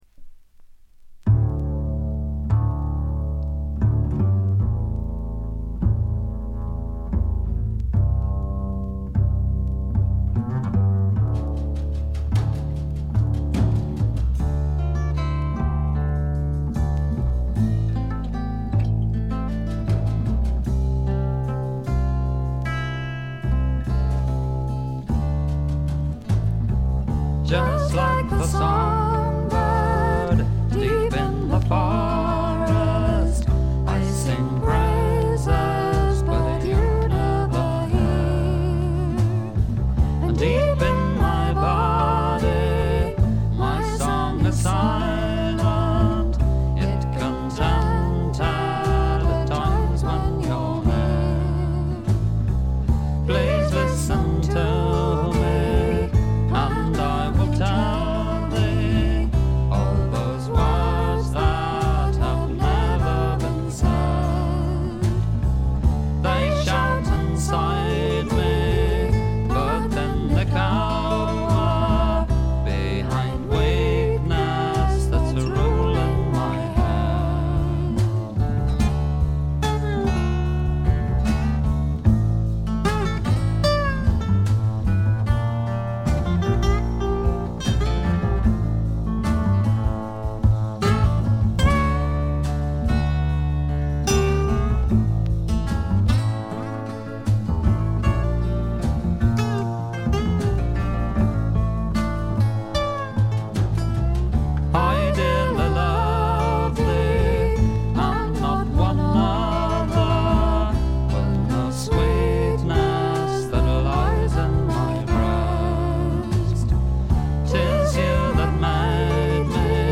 部分試聴ですが軽微なノイズ感のみ。
試聴曲は現品からの取り込み音源です。